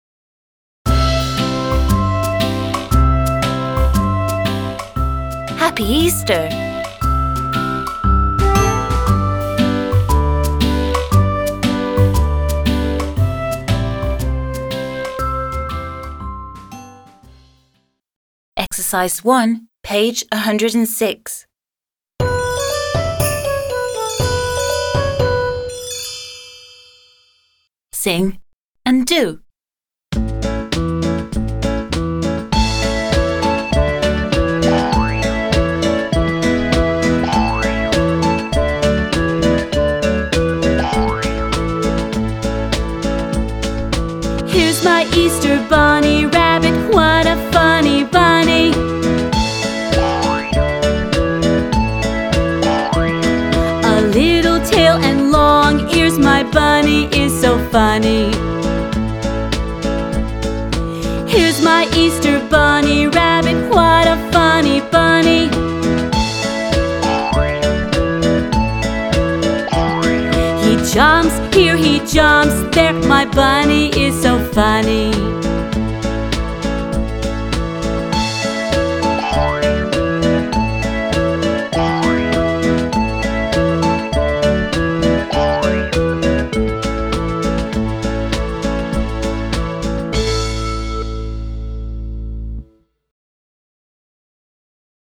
1. Sing and do.